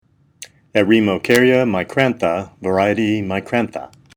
Pronunciation:
E-re-mo-cár-ya mi-crán-tha var. micrantha